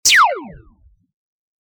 Download Laser Pointer sound effect for free.